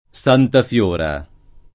Santa Fiora [